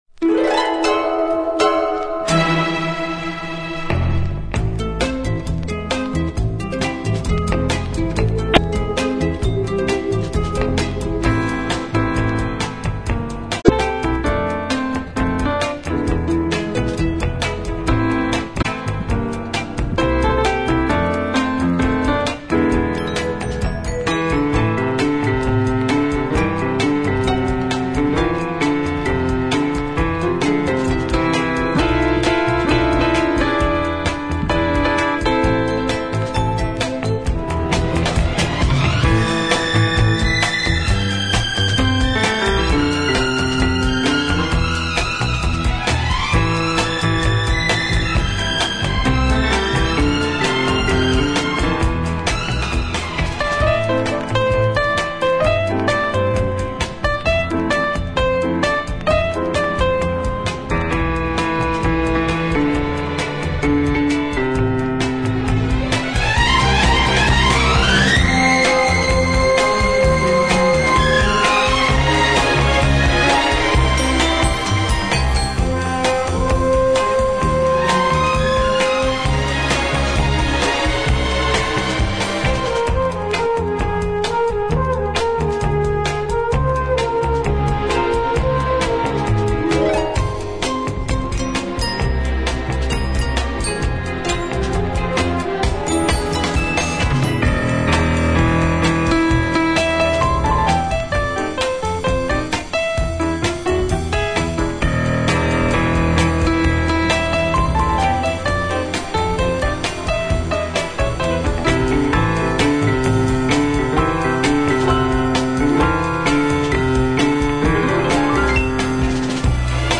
two pianos